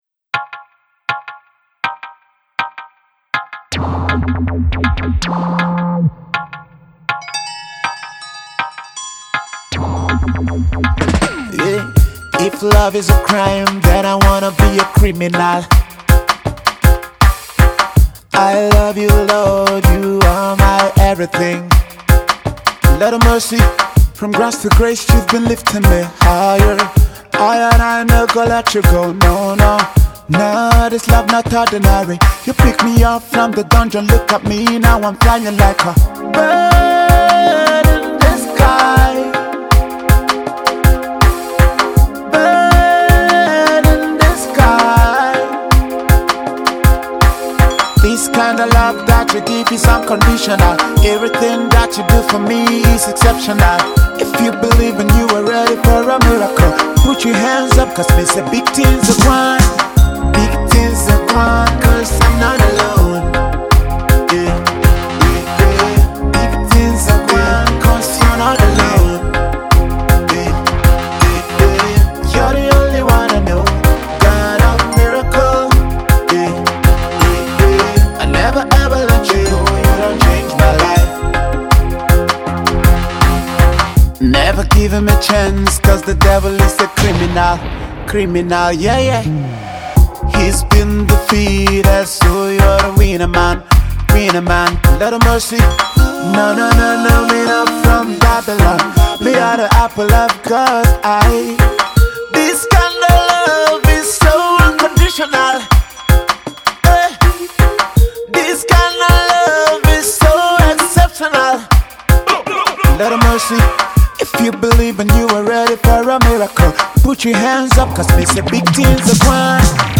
reggae song
‘easy-to-sing-along’ jam